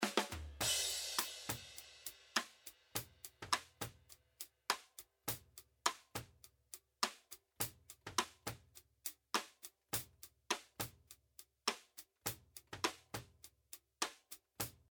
Двойной "бортик" слышен в оверах.
В оверах слышны двойные удары (трель) от основного бортика.
Ведь он есть в двух миках на малом, руме и оверах.